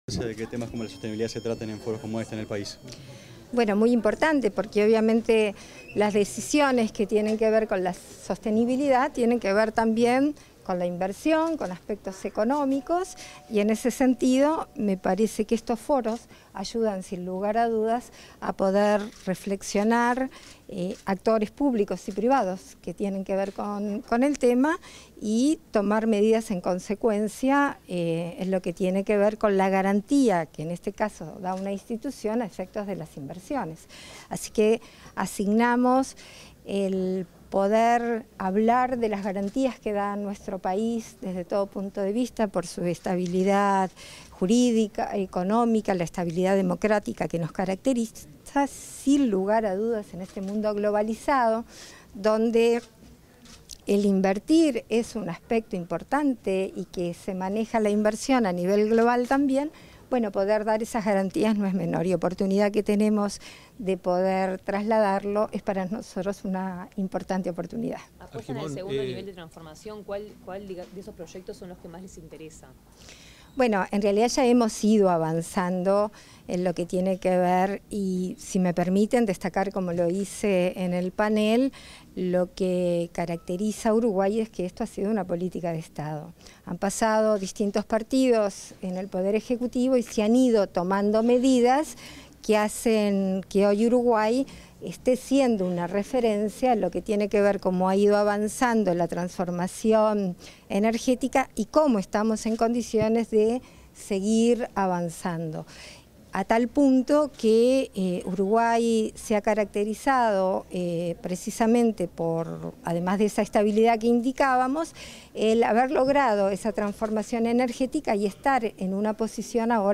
Declaraciones a la prensa de la presidenta de la República en ejercicio, Beatriz Argimón
Declaraciones a la prensa de la presidenta de la República en ejercicio, Beatriz Argimón 30/05/2024 Compartir Facebook X Copiar enlace WhatsApp LinkedIn Tras participar en la apertura del II Foro de Sostenibilidad, organizado por la institución financiera BBVA, este 30 de mayo, la presidenta de la República en ejercicio, Beatriz Argimón, realizó declaraciones a la prensa.